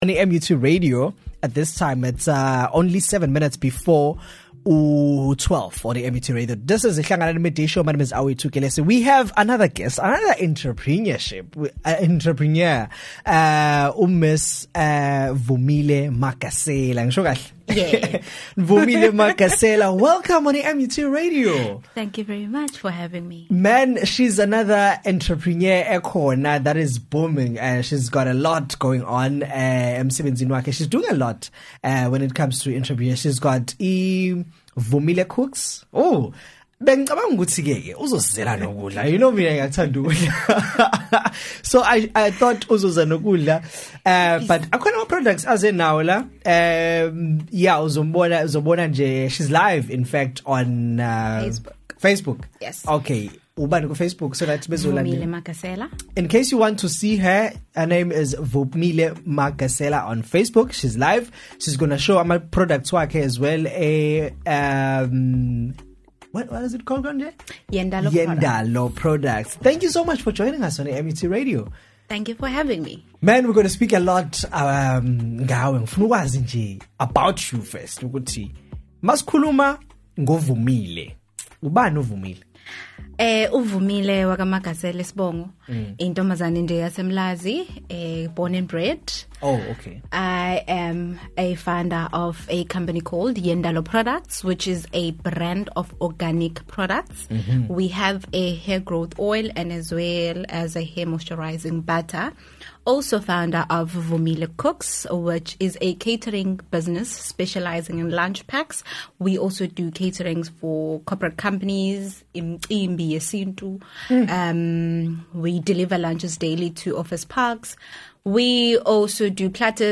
had an interview